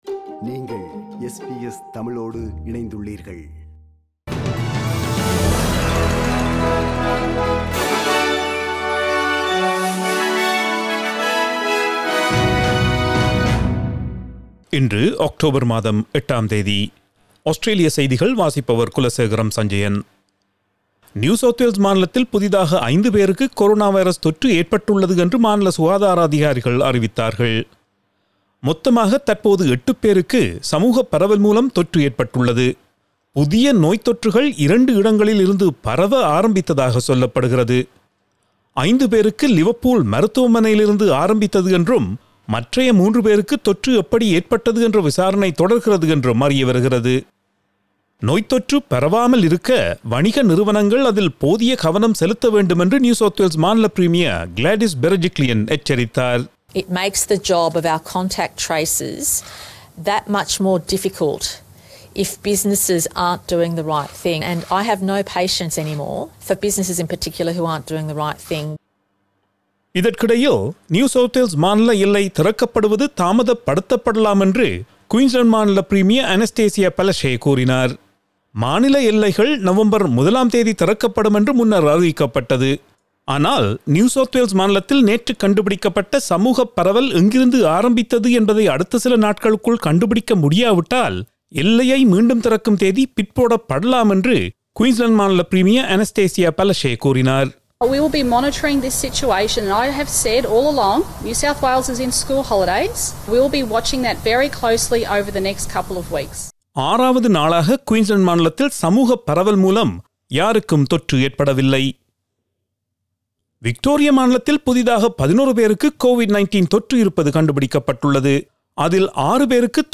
Australian news bulletin for Thursday 08 October 2020.